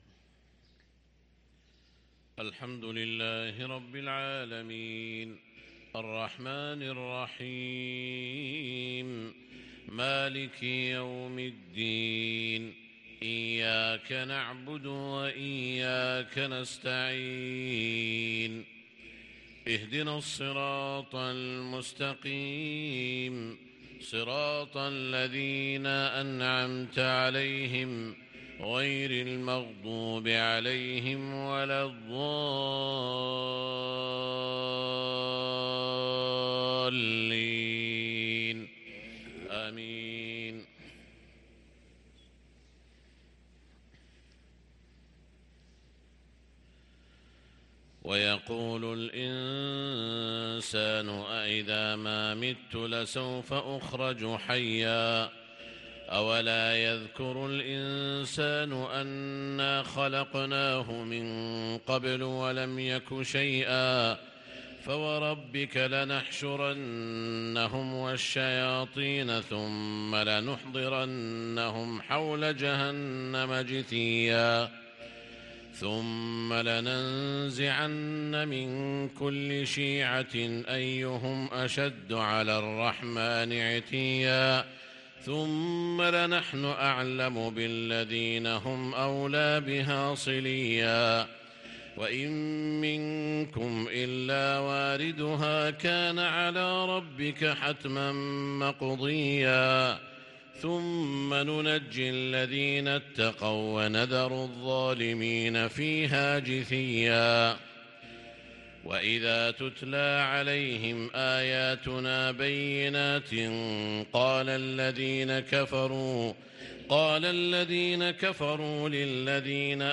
فجر الاثنين 3 محرم 1444هـ خواتيم سورة مريم | Fajr prayer from Surat Maryam 1-8-2022 > 1444 🕋 > الفروض - تلاوات الحرمين